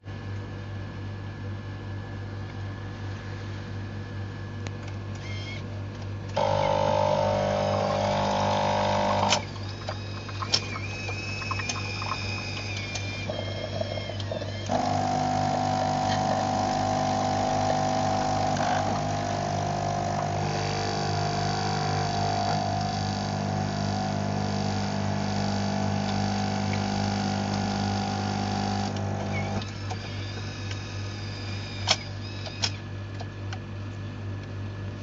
咖啡机 " 咖啡机开放
描述：打开咖啡机
Tag: 厨房 冲泡 时间 设备 蒸汽 热水 咖啡机 咖啡 酿造 家电 水壶 咖啡